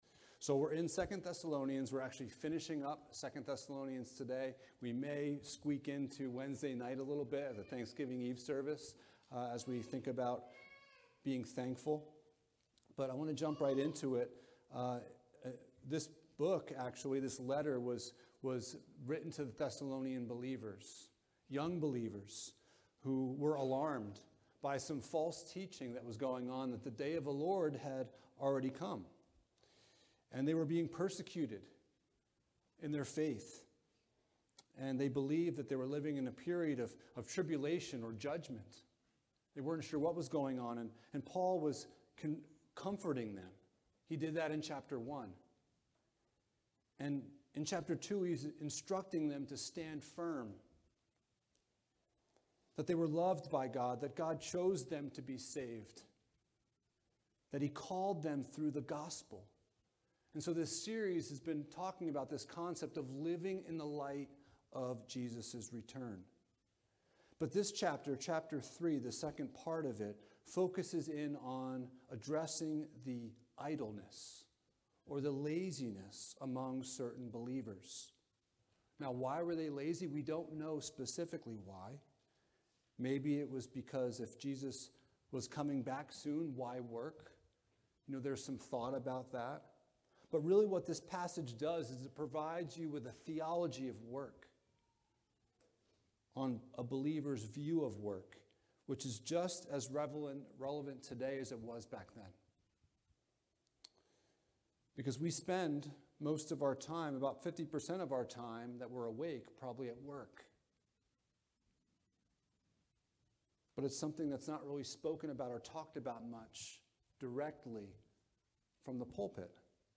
2 Thessalonians 3:6-18 Service Type: Sunday Morning « Does Prayer Really Matter?